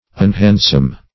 Unhandsome \Un*hand"some\, a.